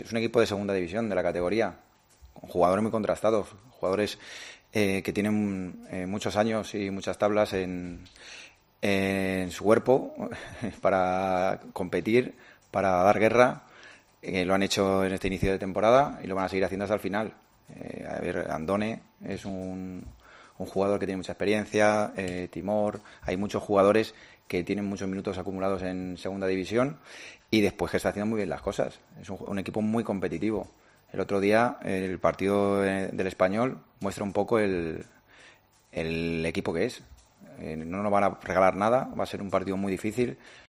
Si consiguen algún punto, será porque se lo han ganado ellos”, dijo en la rueda de prensa previa al partido.